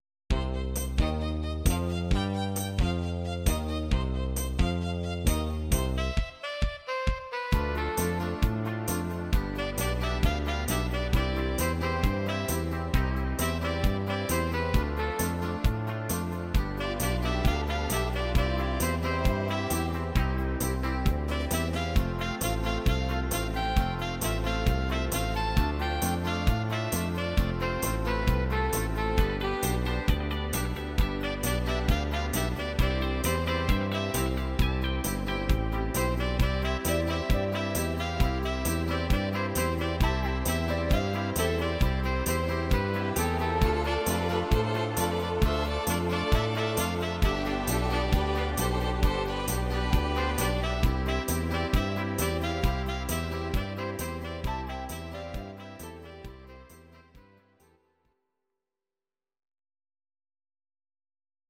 Audio Recordings based on Midi-files
Country, 1970s